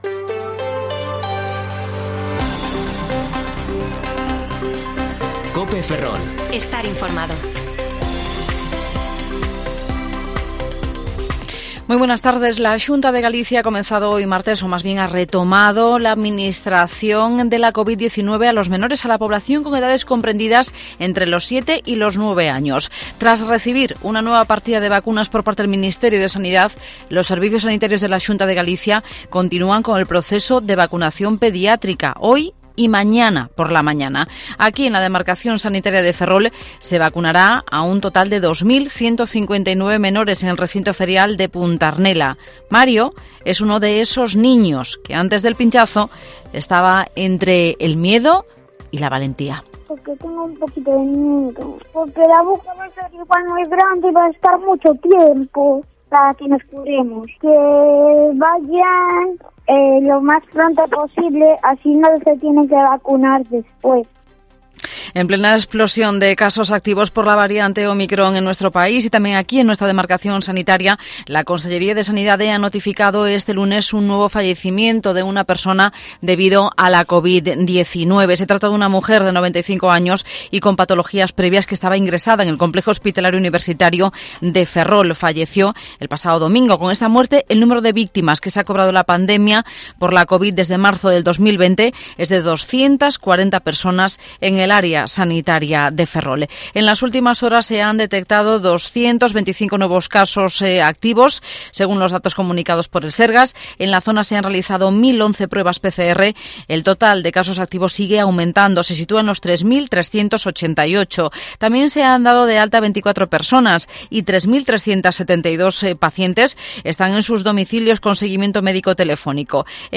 Informativo Mediodía COPE Ferrol 4/1/2022 (De 14,20 a 14,30 horas